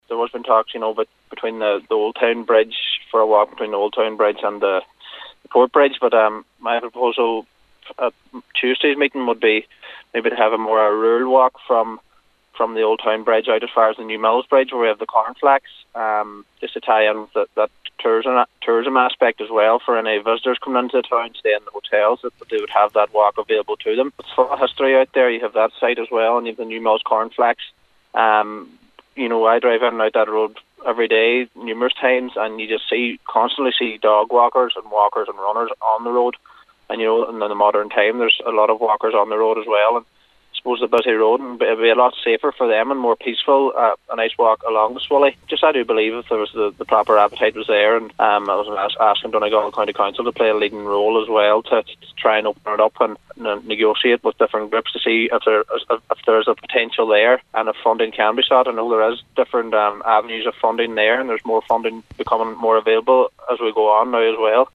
Local Cllr. James Pat McDaid says it’s a popular route among pedestrians but a walkway would make it safer for all: